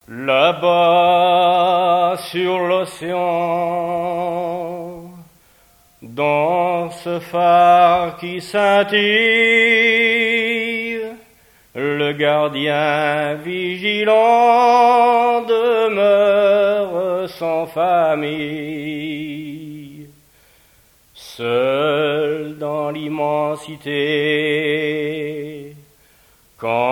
Mémoires et Patrimoines vivants - RaddO est une base de données d'archives iconographiques et sonores.
chansons tradtionnelles
Pièce musicale inédite